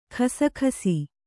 ♪ khasaKhasi